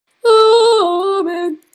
A-Aaamen (auto-tune edition)
aamenautotune.mp2_.mp3